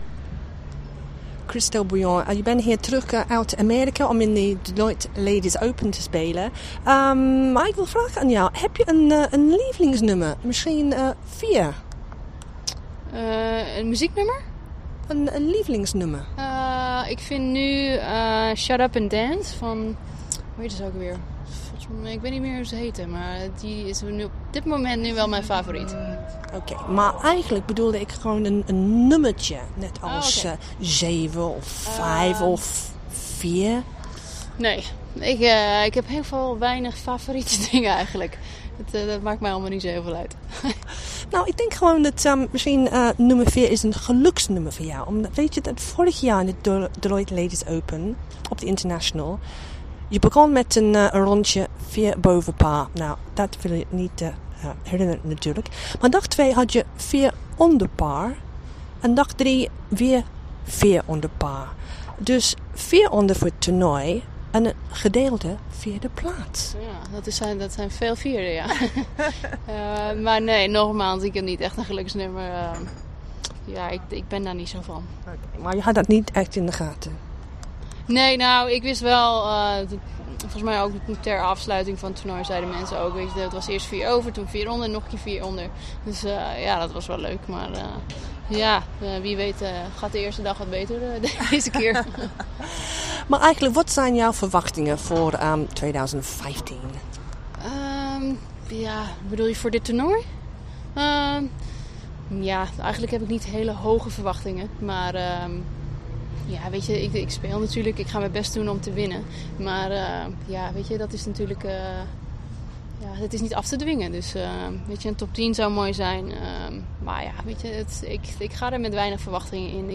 DUTCH radio interview 2 min 52 sec.